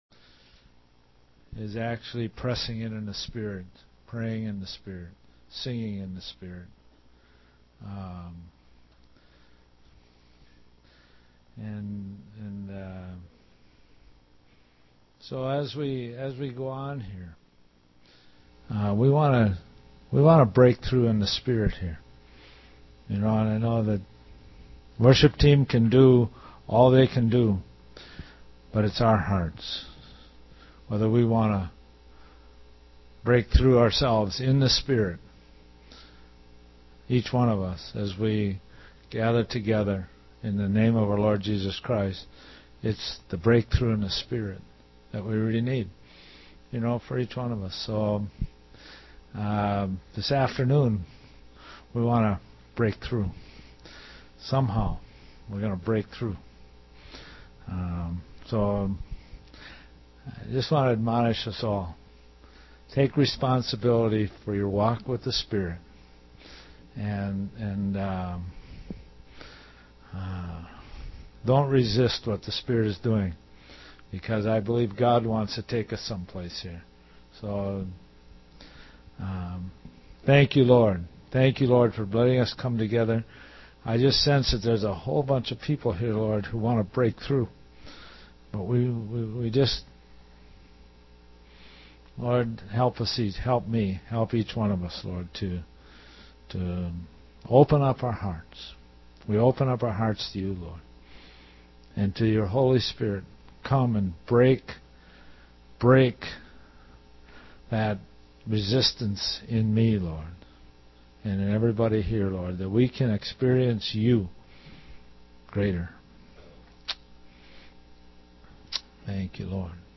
living in the Spirit. 120218ProphConSess4 This entry was posted in sermons .